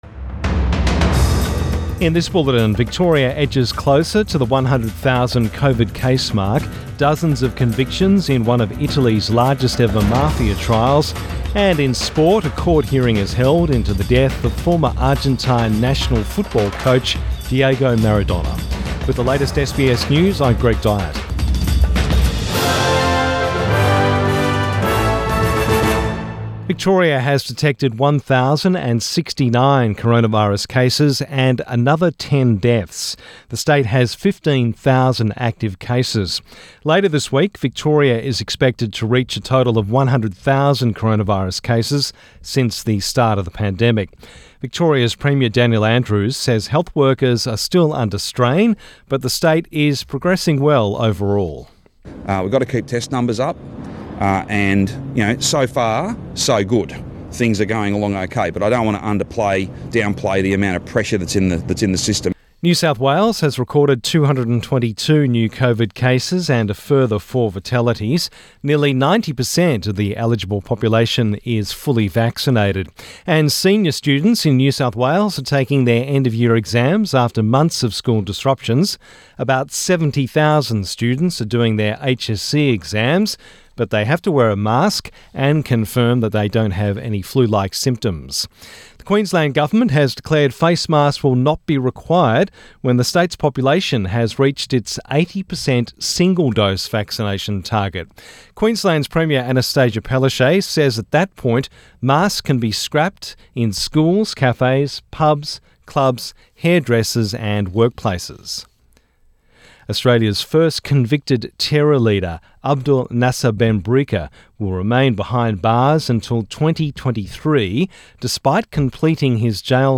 Midday bulletin 9 November 2021